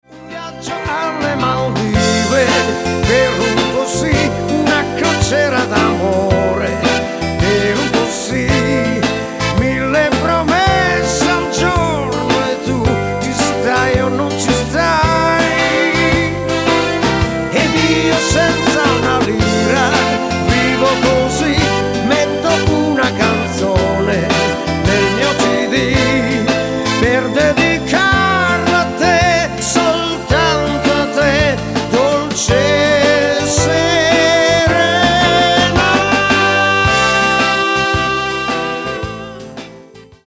MODERATO SWING  (3.11)